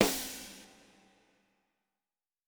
TC PERC 01.wav